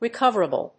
音節re・cov・er・a・ble 発音記号・読み方
/rɪkˈʌv(ə)rəbl(米国英語), rɪˈkʌvɜ:ʌbʌl(英国英語)/